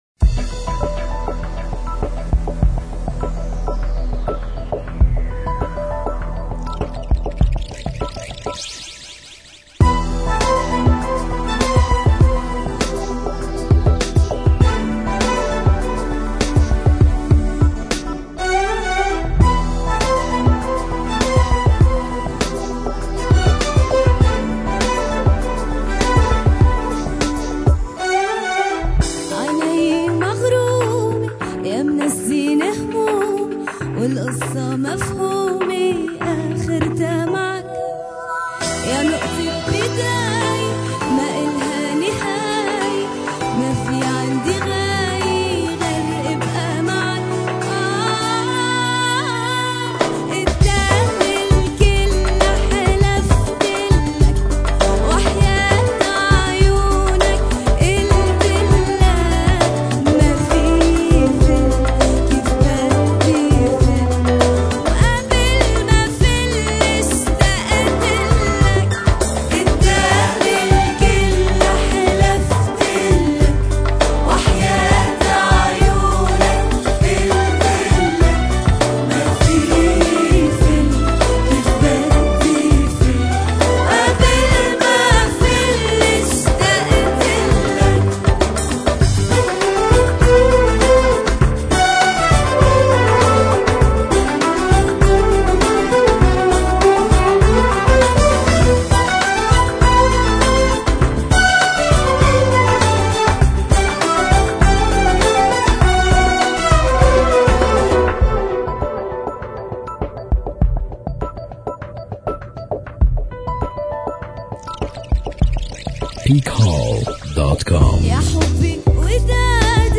Arabic Karaoke Talented Singers